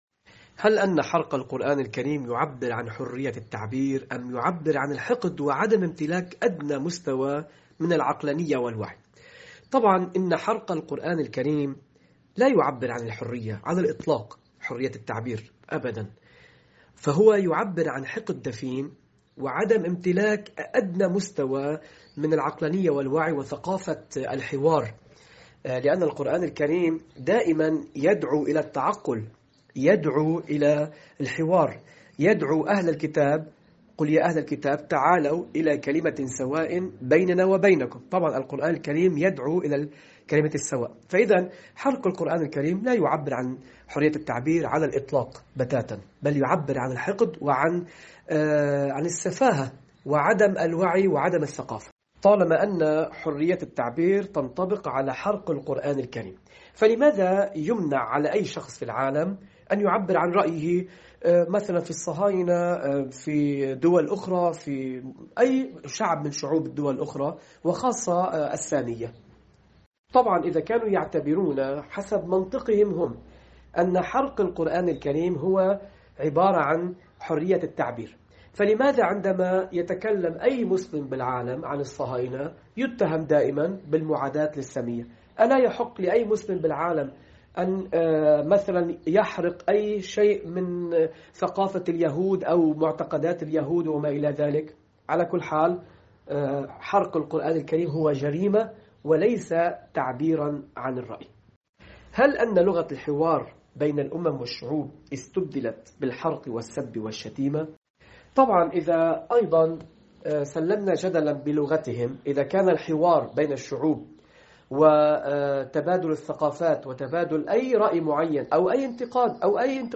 حواراً